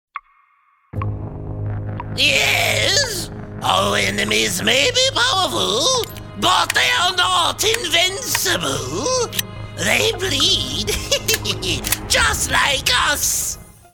Male
Adult (30-50), Older Sound (50+)
Character / Cartoon